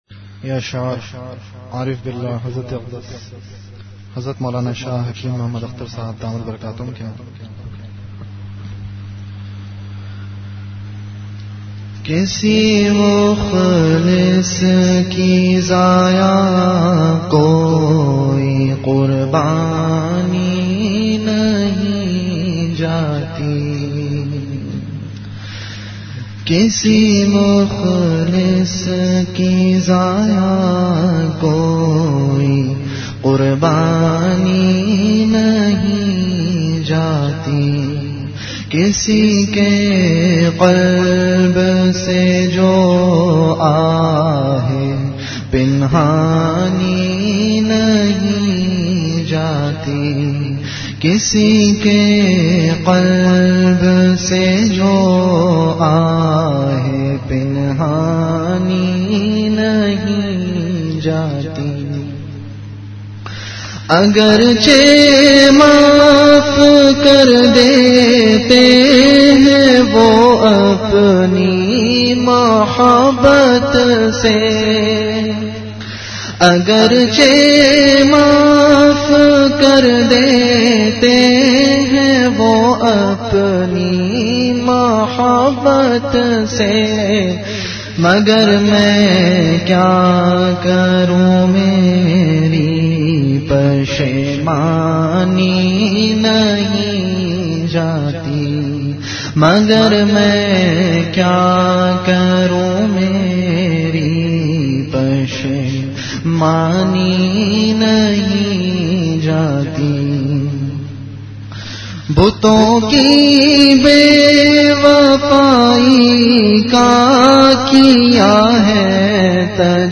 CategoryAshaar
Event / TimeAfter Isha Prayer